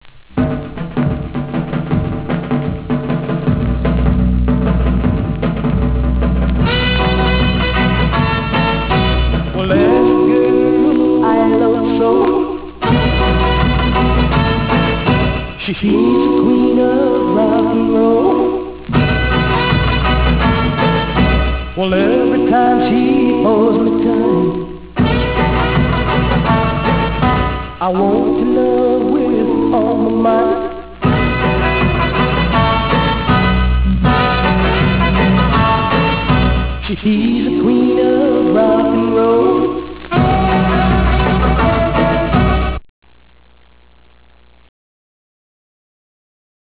Garage Rock WAV Files